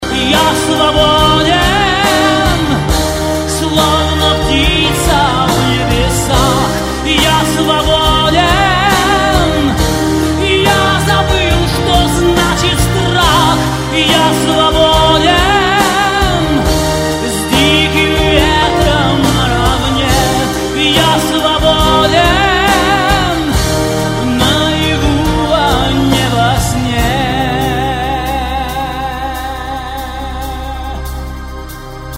Главная » Файлы » Рок